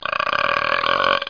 bigburp.mp3